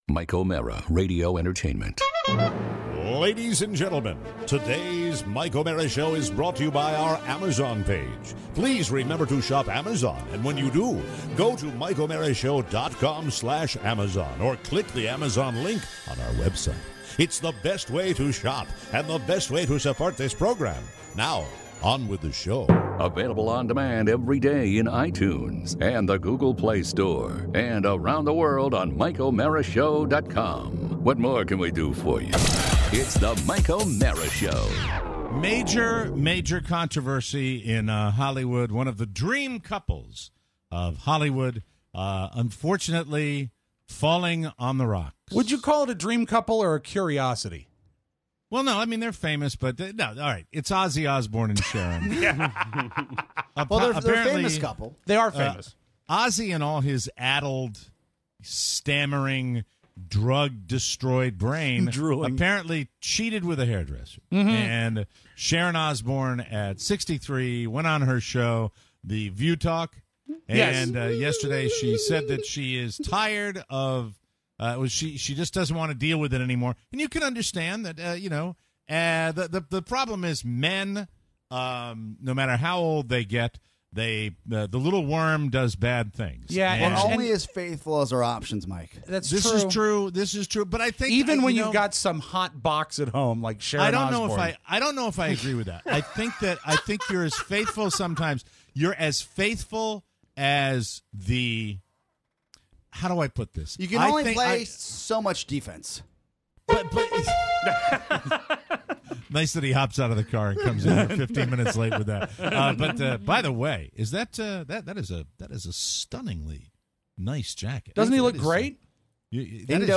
Your calls! Plus, our Ozzy Osbourne expert… Caps love… Prince and pills… television… and the sawbones.